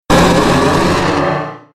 Cri de Méga-Dardargnan dans Pokémon Rubis Oméga et Saphir Alpha.